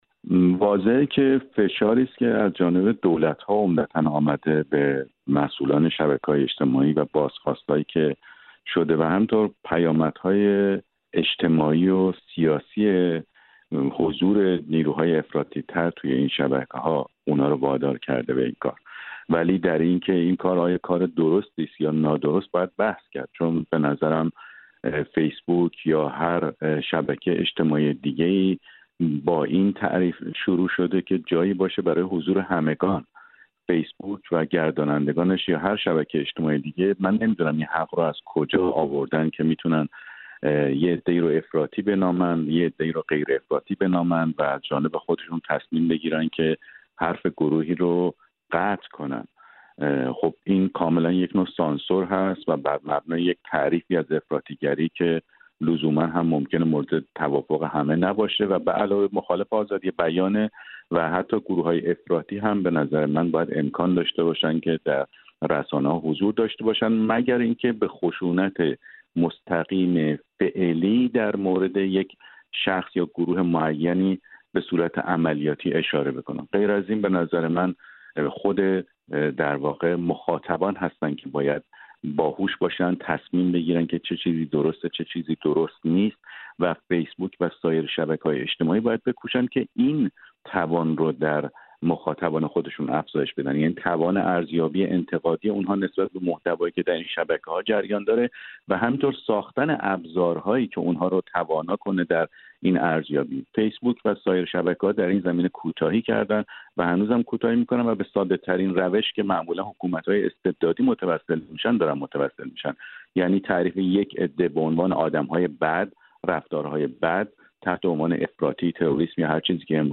هم‌زمان اینستاگرام نیز به طور آزمایشی نمایش تعداد لایک‌ها را در کانادا محدود کرده است.